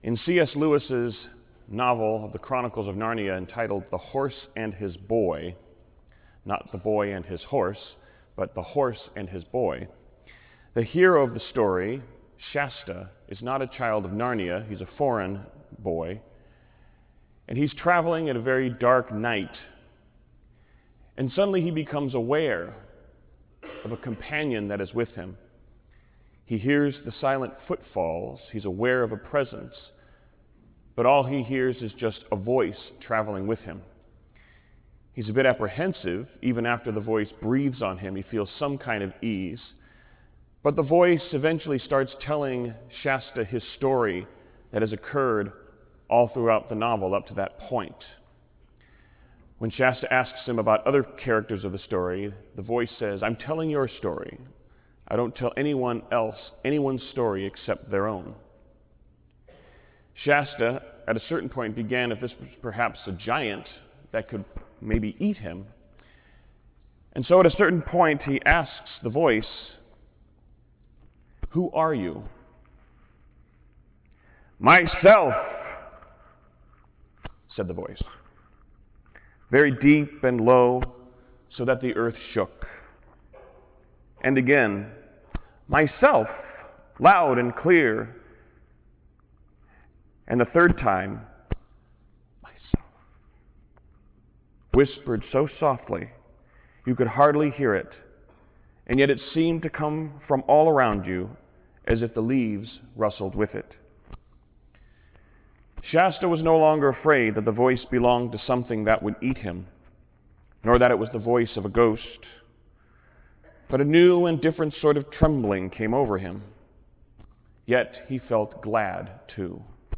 Homily-TrinitySundayC.wav